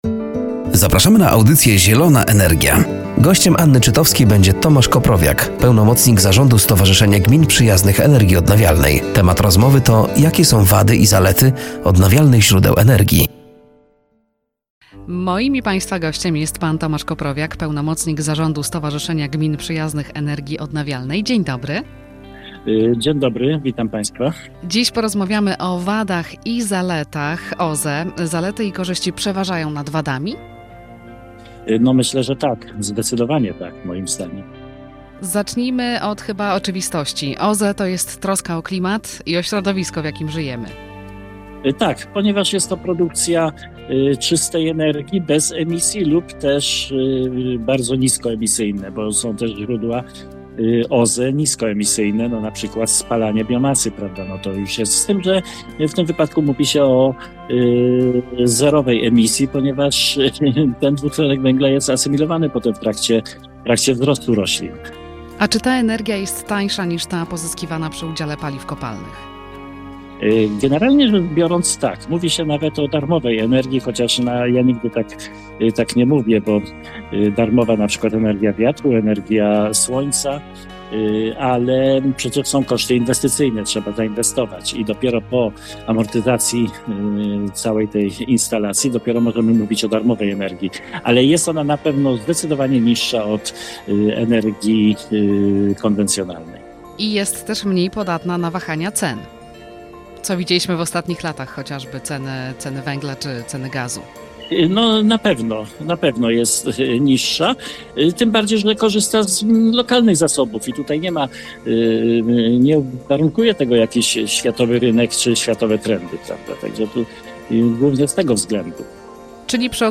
Z rozmowy dowiemy się jakie są wady i zalety odnawialnych źródeł energii. Czy OZE w każdym położeniu geograficznym są tak samo wydajne?